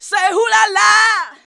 SAY 2.wav